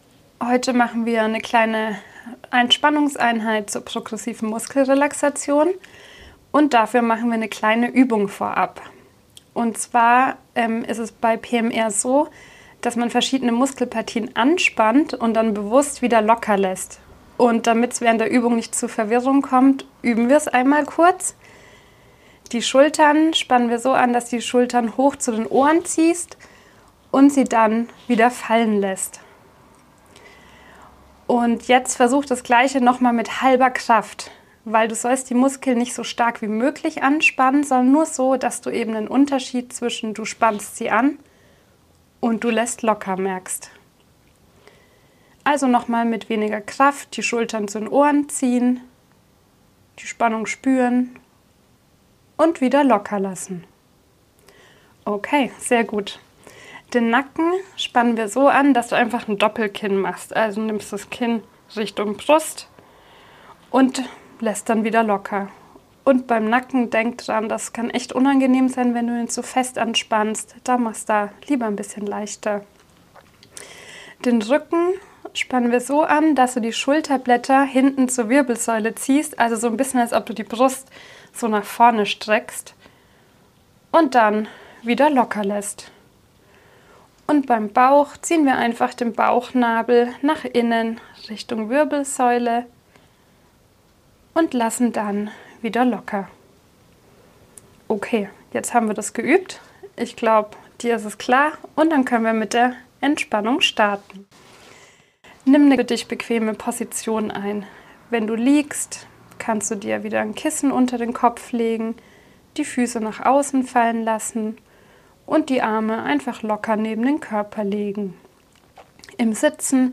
Entspannungsübung